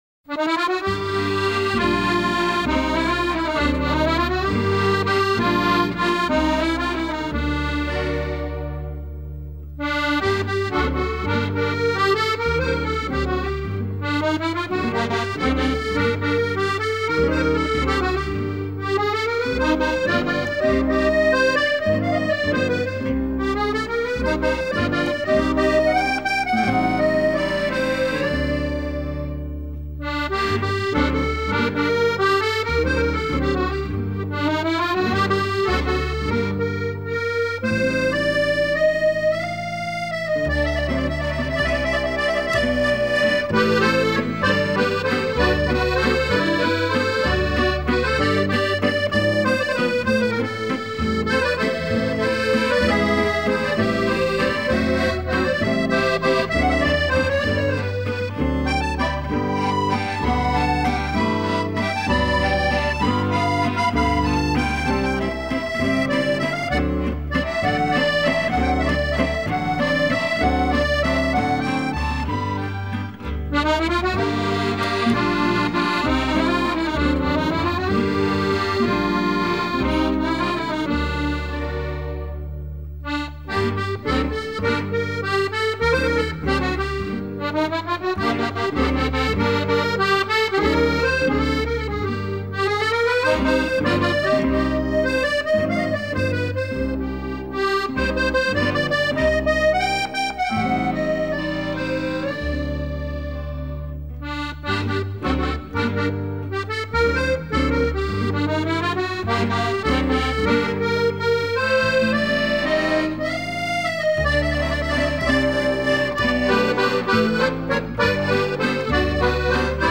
1 片装 专辑简介： 本专辑是以手风琴演奏为主题，散发巴黎浪漫慵懒的气氛。